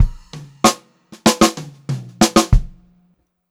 96PO2FILL1-R.wav